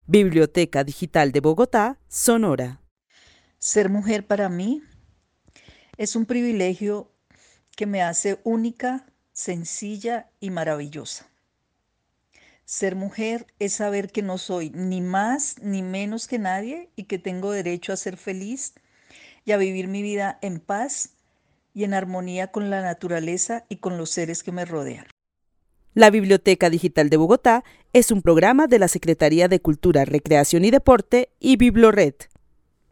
Narración oral sobre lo que significa ser mujer. La narradora es una habitante de Bogotá que considera privilegiada de ser mujer. Resalta que serlo no la hace menos ni más que otro ser humano y que tiene derecho a vivir en paz. El testimonio fue recolectado en el marco del laboratorio de co-creación "Postales sonoras: mujeres escuchando mujeres" de la línea Cultura Digital e Innovación de la Red Distrital de Bibliotecas Públicas de Bogotá - BibloRed.